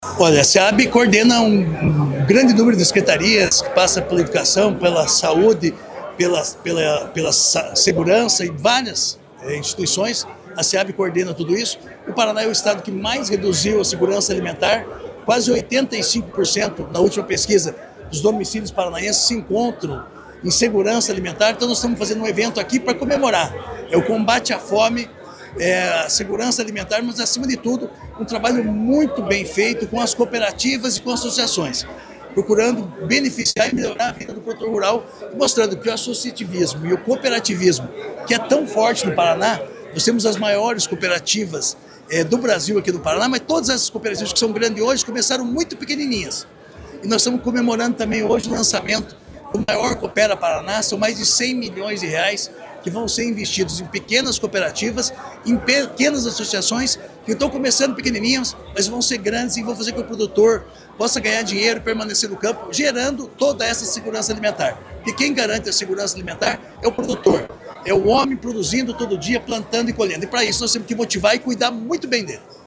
Sonora do secretário da Agricultura e do Abastecimento, Márcio Nunes, sobre os programas do Estado que garantem segurança alimentar | Governo do Estado do Paraná